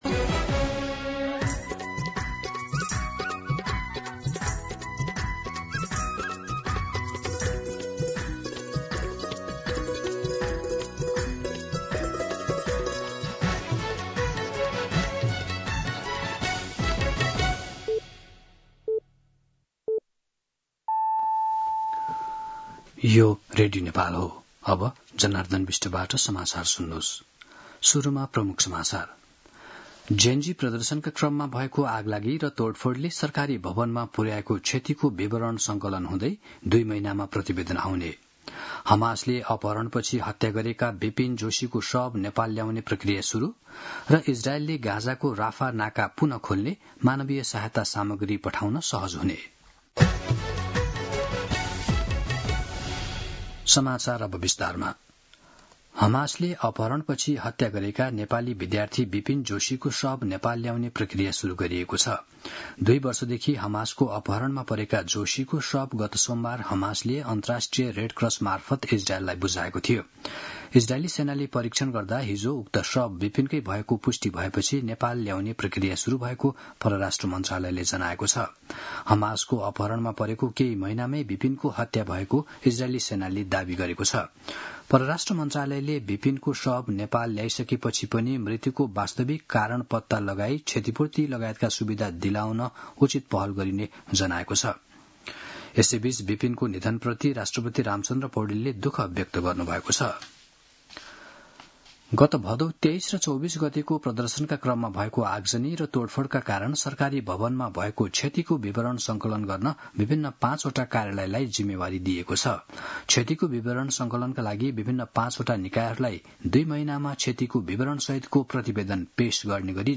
दिउँसो ३ बजेको नेपाली समाचार : २९ असोज , २०८२